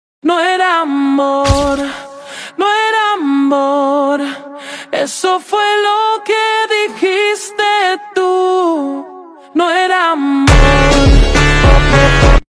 Troll Face Phonk